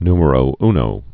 (nmə-rō nō, ny-)